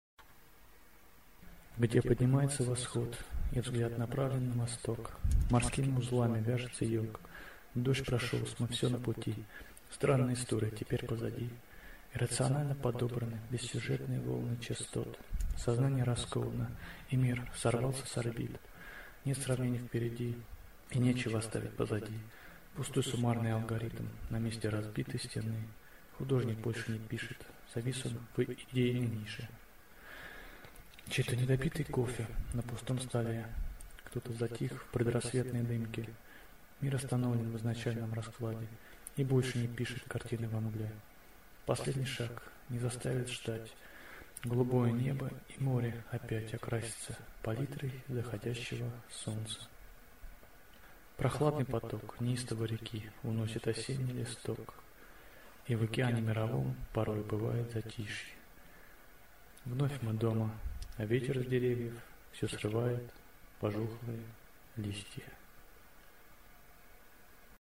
Стихотворение